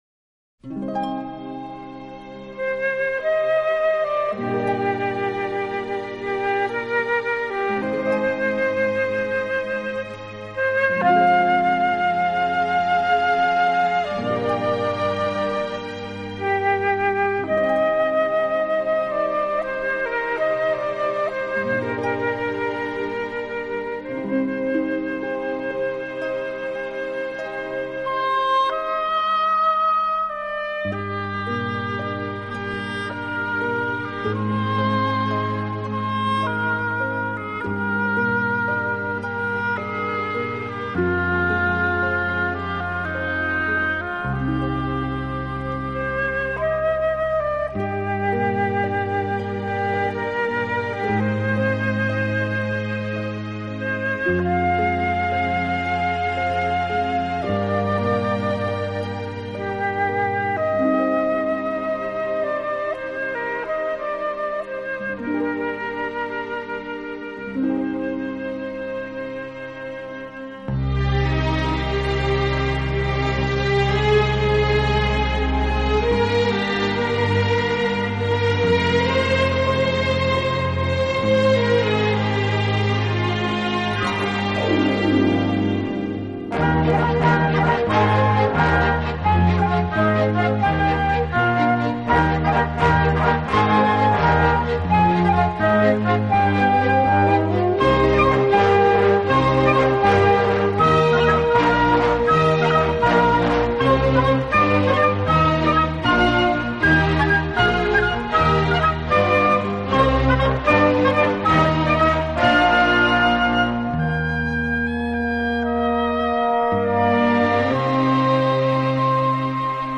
轻弹心弦柔情音韵，经典旋律经典再现！